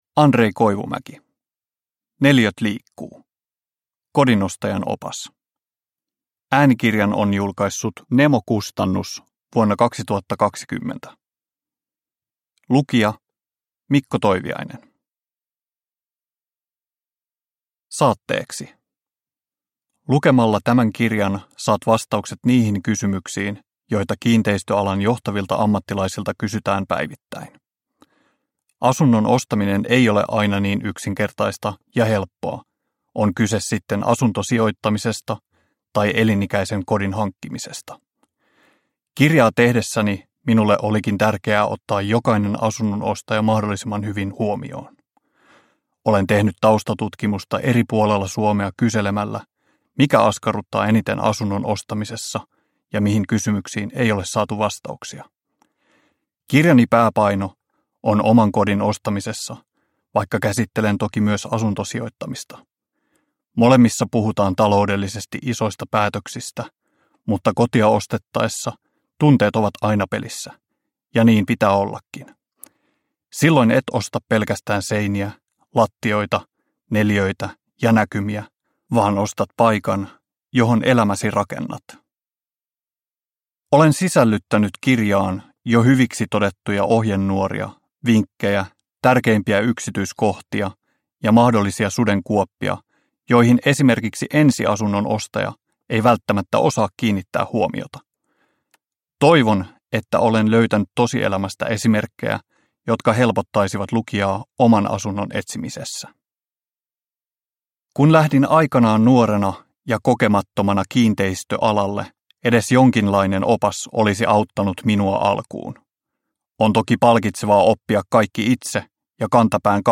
Neliöt liikkuu - kodinostajan opas – Ljudbok – Laddas ner